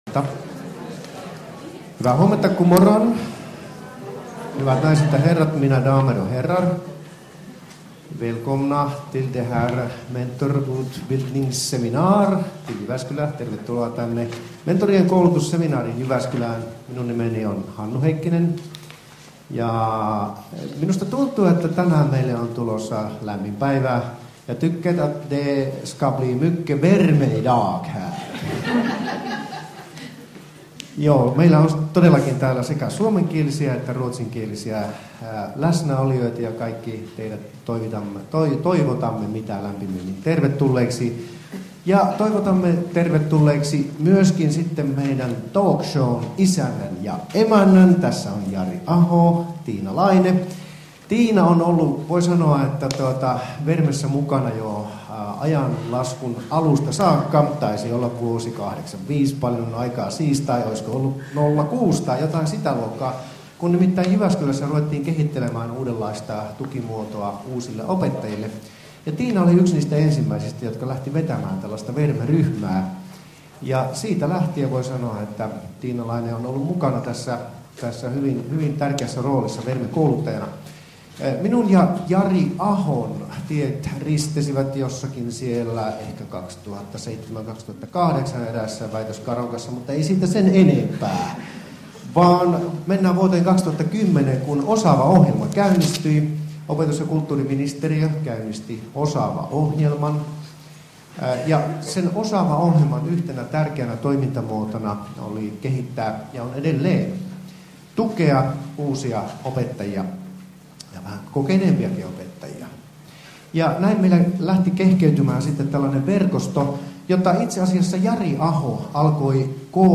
Osaava Verme - mentorikoulutuksen valtakunnallinen avausseminaari 2013 - Osa 1